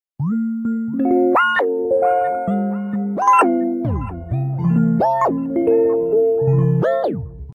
What my heartbeat sounds like sound effects free download